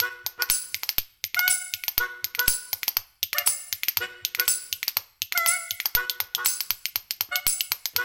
Spoons